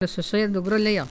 Elle provient de Saint-Jean-de-Monts.
Catégorie Locution ( parler, expression, langue,... )